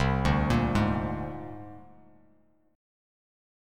C7sus2#5 chord